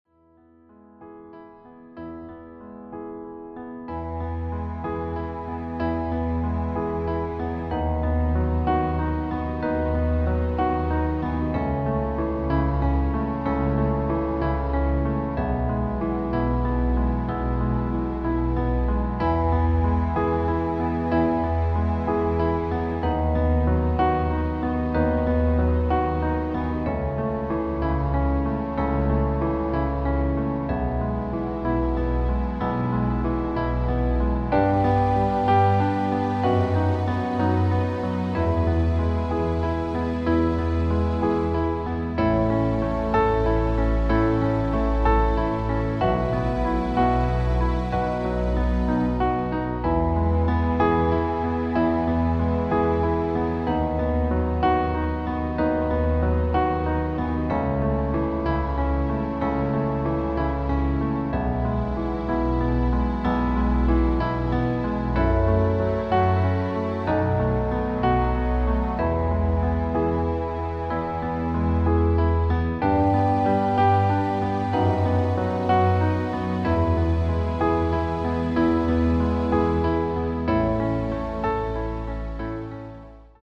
• Art: Klavierversion mit Streichern
Demo in E Dur:
• Das Instrumental beinhaltet NICHT die Leadstimme